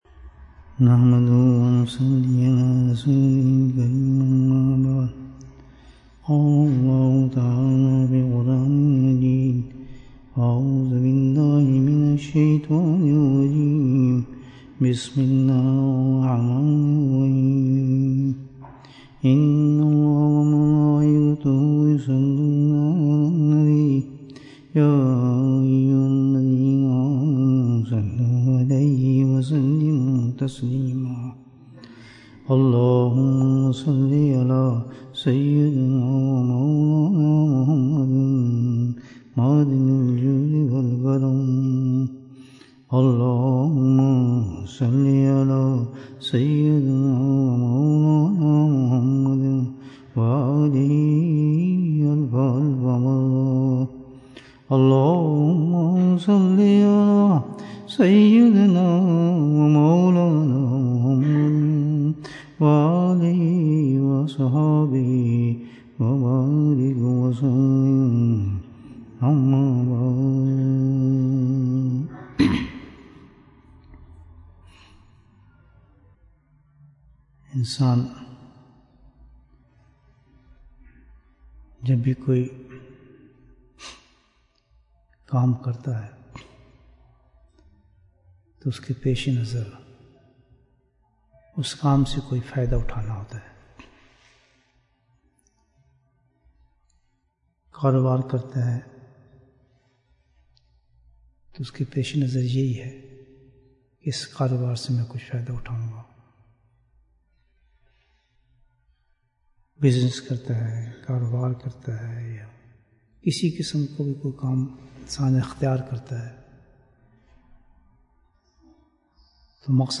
اعمال کی حفاظت کیسے کریں؟ Bayan, 51 minutes17th September, 2020